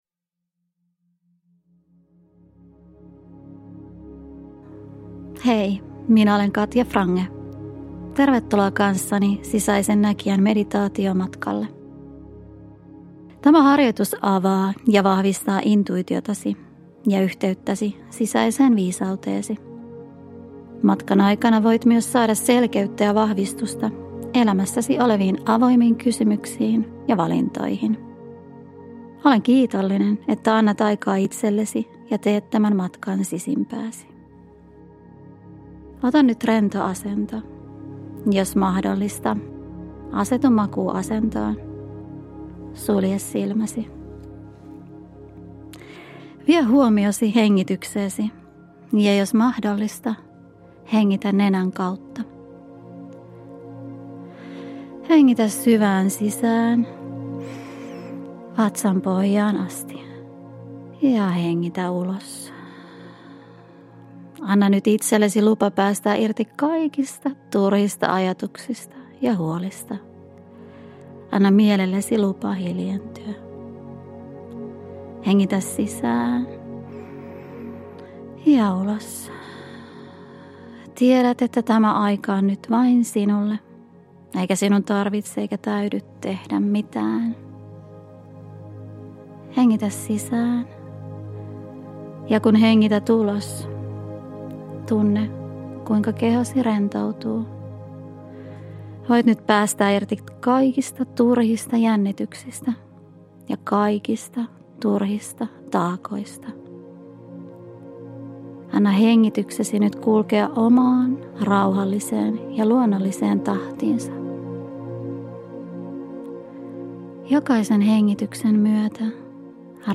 Sisäinen näkijä -meditaatio – Ljudbok – Laddas ner